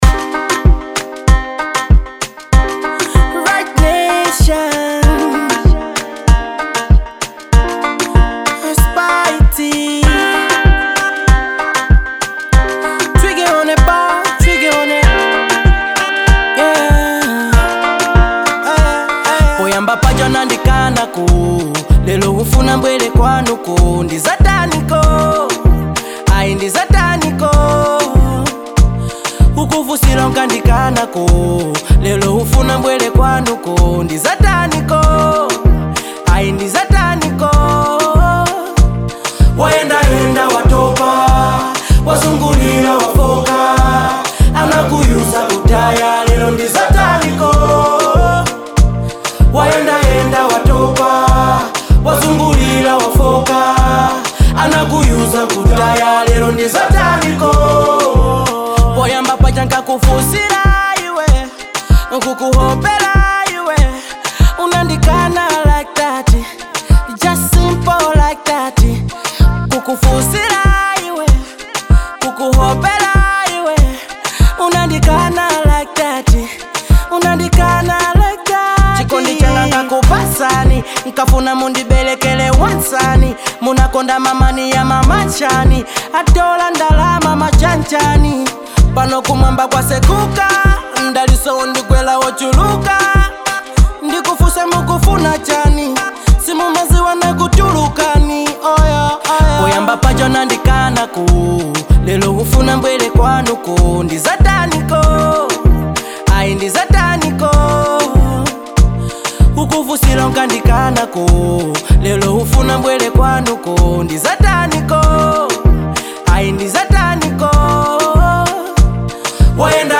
Genre Afrobeat